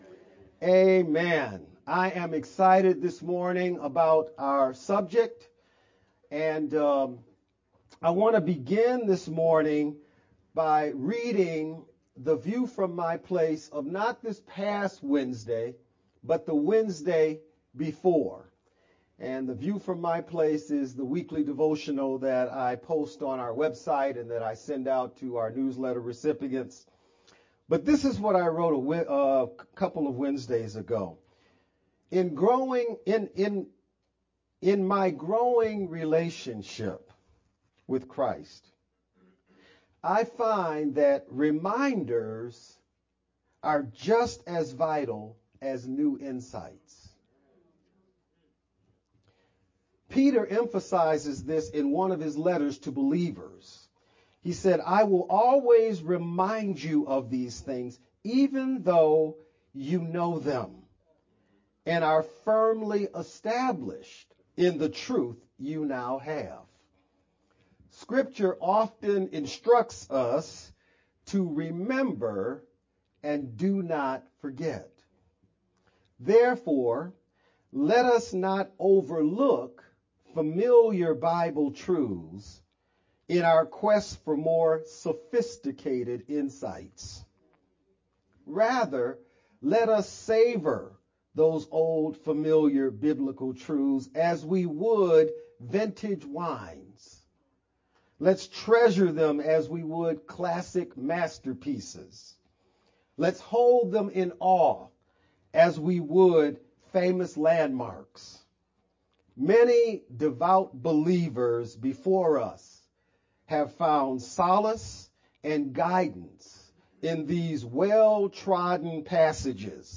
June-9th-VBCC-Sermon-only-edited_Converted-CD.mp3